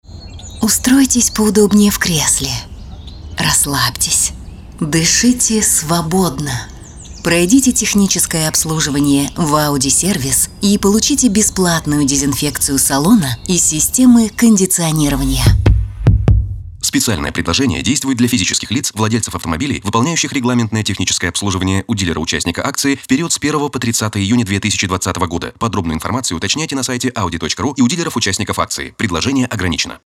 Ауди медитация
Жен, Рекламный ролик/Молодой